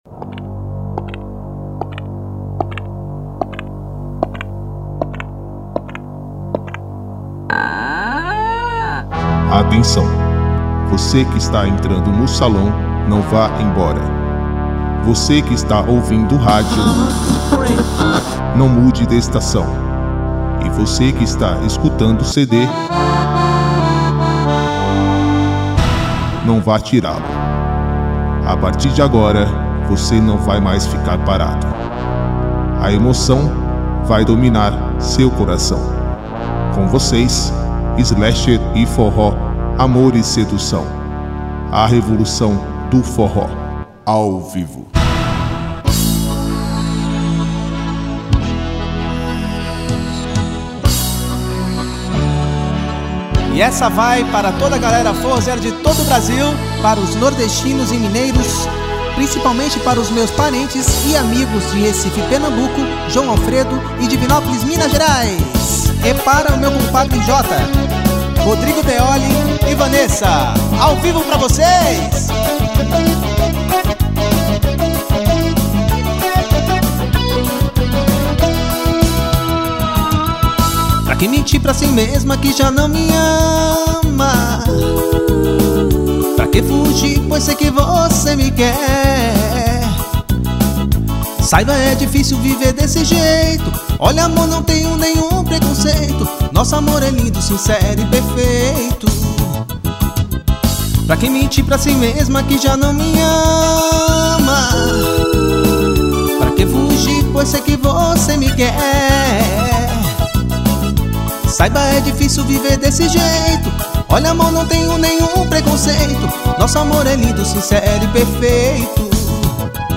Teclados